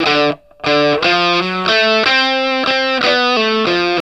WALK1 60 D.A.wav